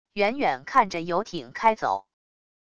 远远看着游艇开走wav音频